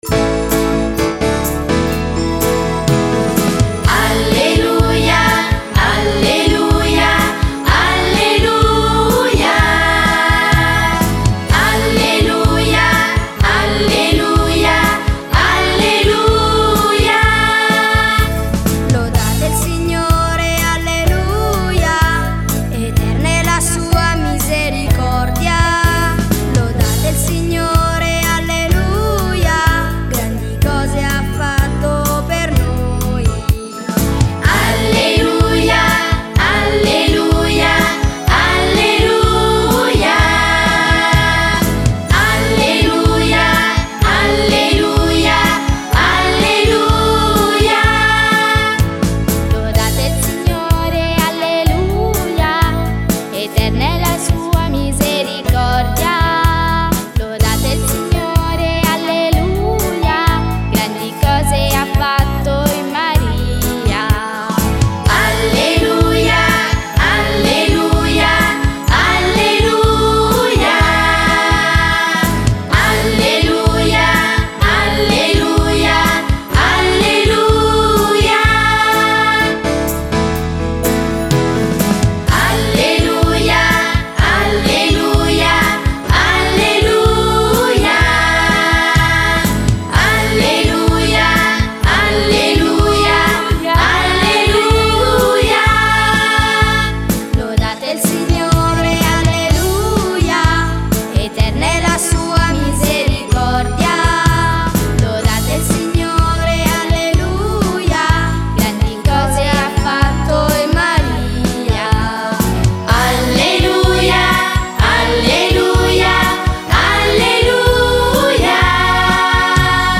Cenacolo Gam Pasqua di Risurrezione Domenica 5 aprile
Canto per Rosario e Parola di Dio: Alleluia, eterna è la sua misericordia